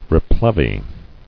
[re·plev·y]